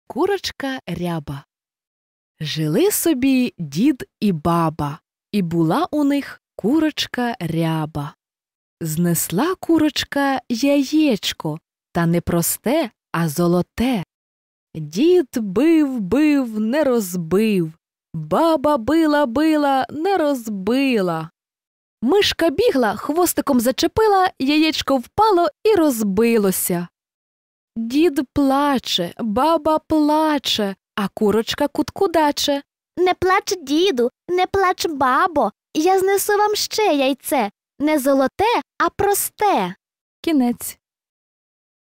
Аудіоказка “Курочка Ряба” українською – слухати та скачати безкоштовно в форматах MP3 і M4A
Аудіоказки для маленьких діточок: слухати і скачати